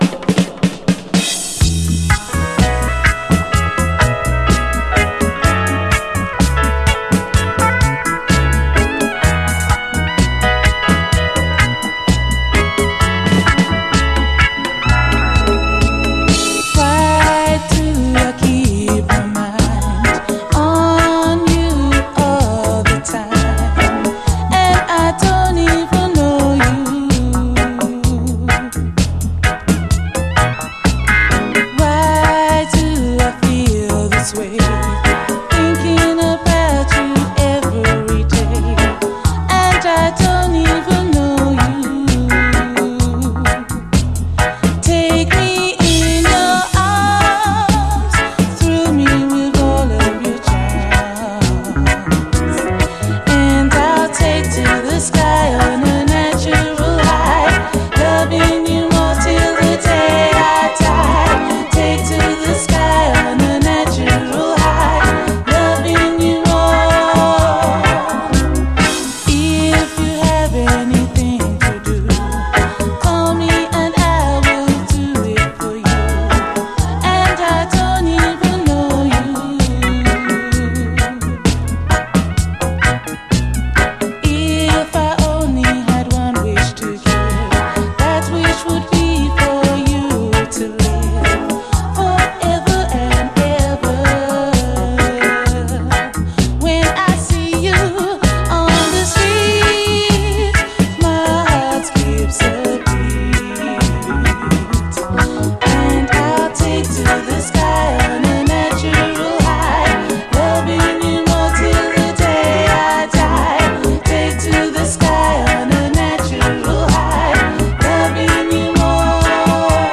REGGAE
ピュイーンと伸びやかなシンセ使い、そして絶妙に悩ましいサビのメロディー・アレンジにヤラれる最高トラック！